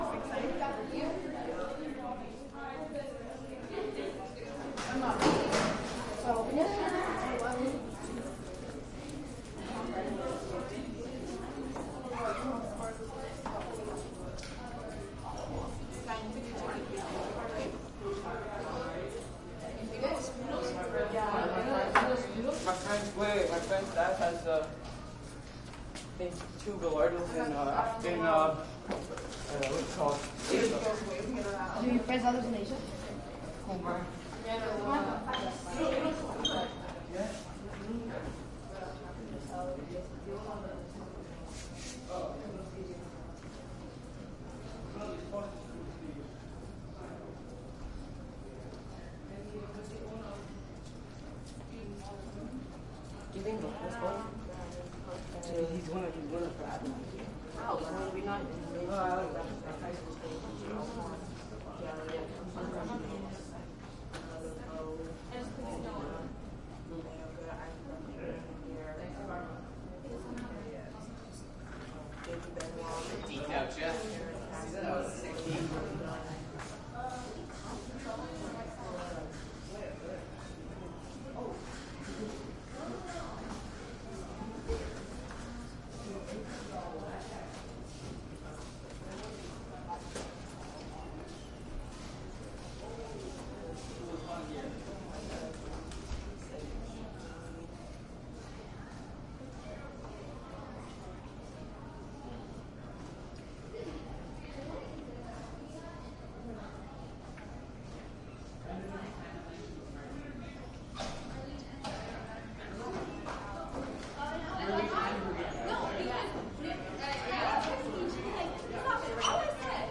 高中 " 人群中的高中走廊轻度至中度活跃的学生
描述：人群int高中走廊轻到中等活跃的学生
Tag: 重量轻 INT 人群 学校 走廊